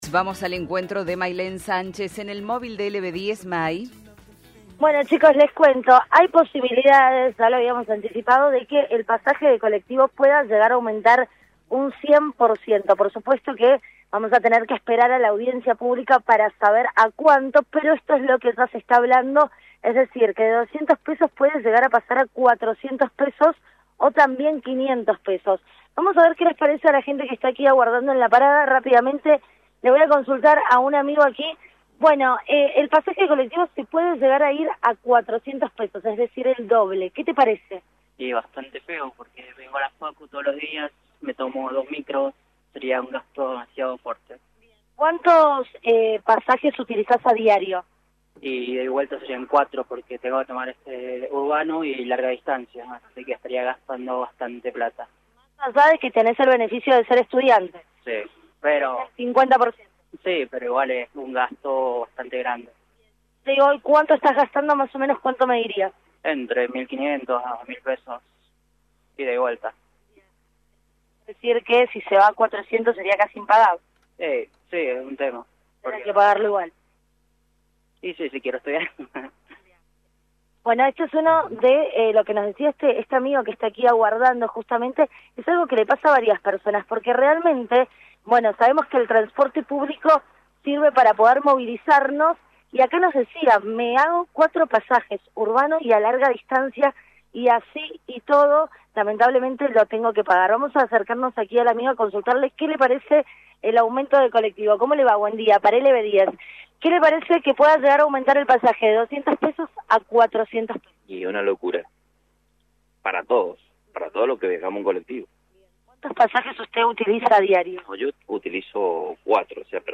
LVDiez - Radio de Cuyo - Móvil de LVDiez- Rechazo de pasajeros a aumento del boleto